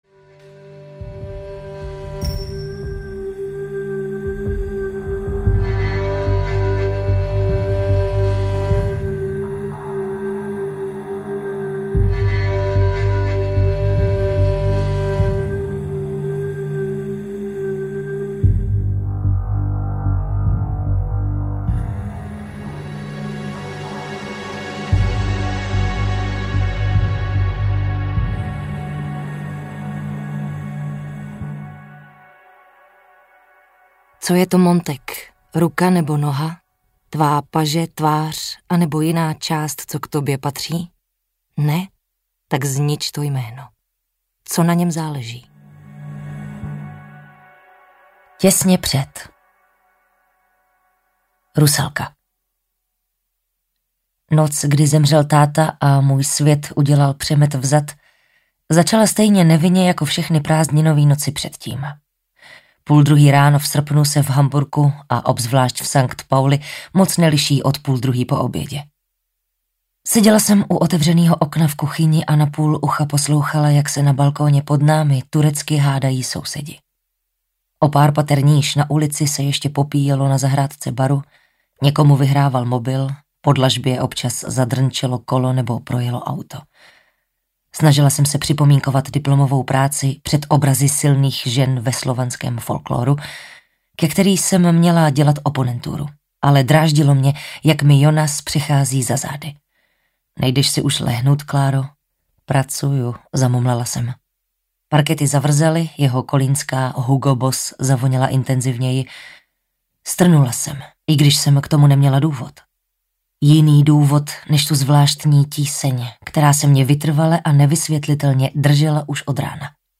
Znič to jméno audiokniha
Ukázka z knihy
znic-to-jmeno-audiokniha